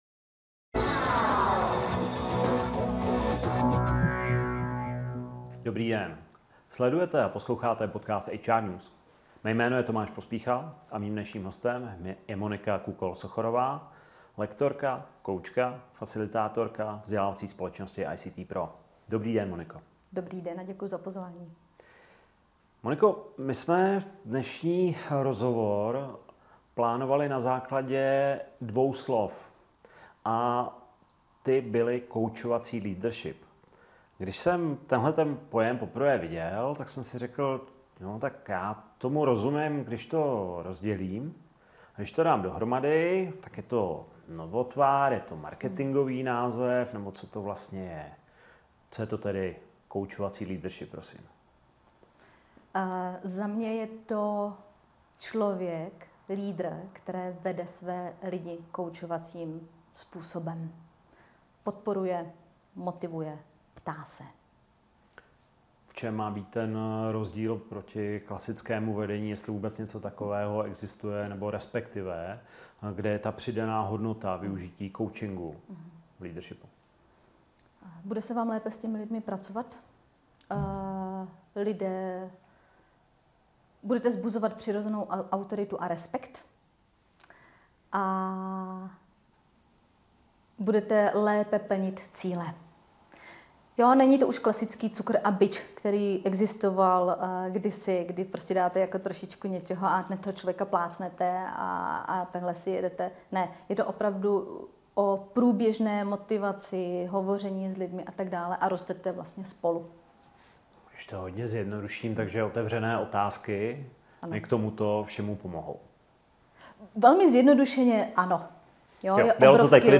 Přehrát zvukový záznam videa V rozhovoru se dozvíte: Leadership není o příkazech: Proč klasický přístup „cukru a biče“ už dnes nefunguje a jak ho nahradit skutečným partnerstvím.